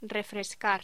Sonidos: Voz humana